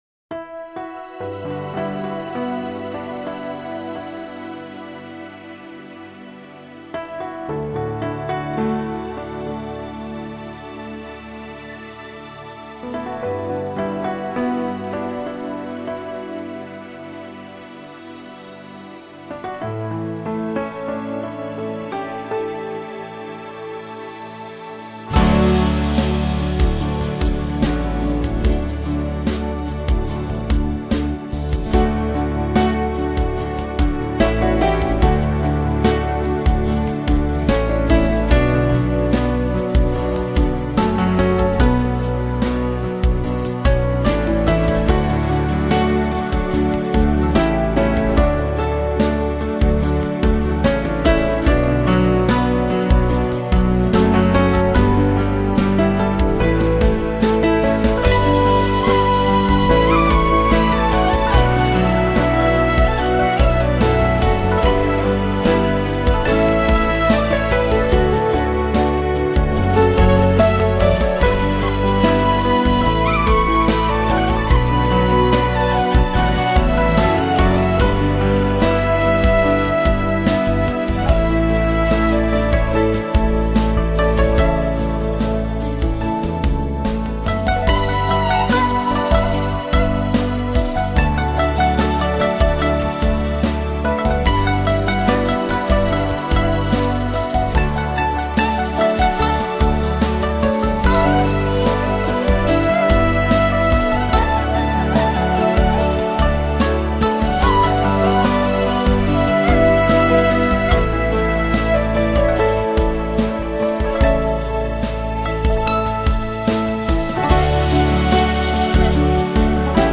飘逸的曲子
清清淡淡
如行云流水般的音律
洁净而从容